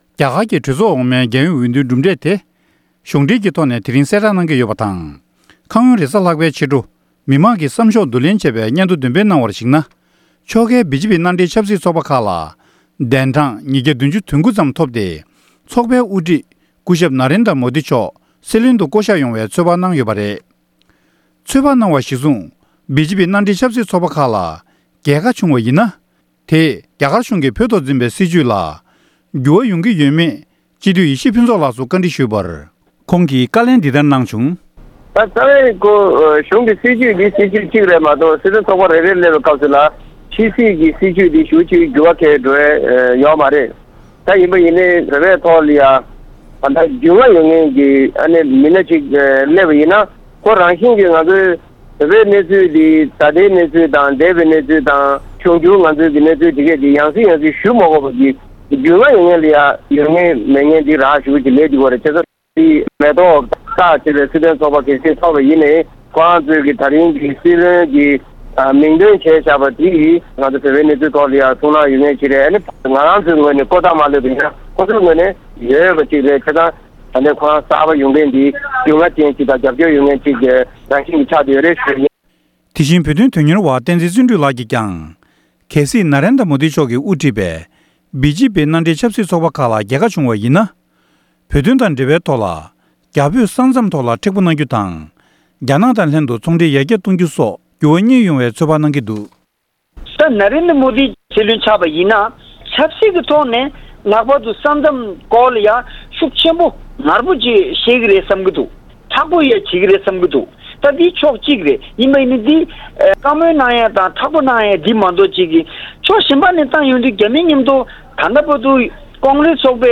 ༄༅། །གསར་འགྱུར་དང་འབྲེལ་བའི་ལེ་ཚན་ནང་།